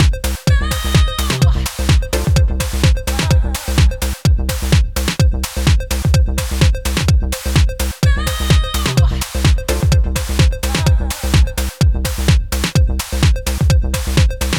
I recorded a clip straight from the Tangerine, and also the same clip but from the Tangerine through the nts-3 with effects off.